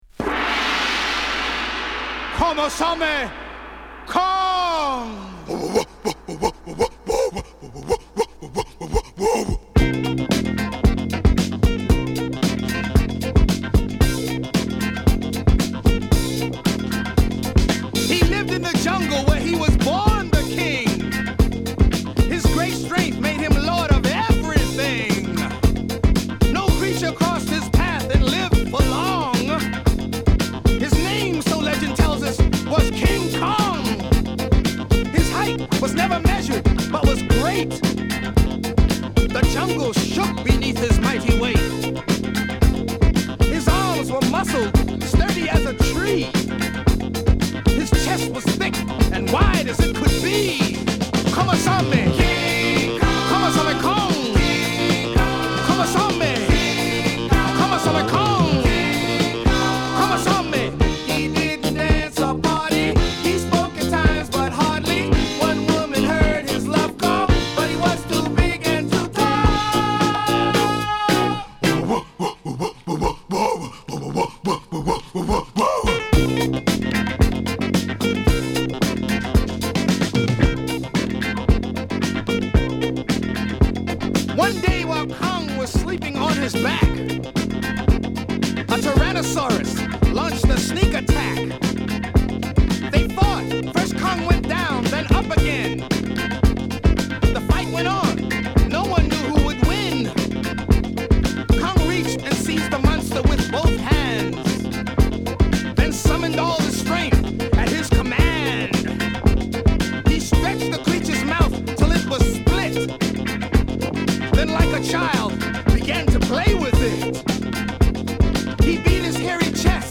Hip Hop〜レアグルーヴ〜ディスコまで！...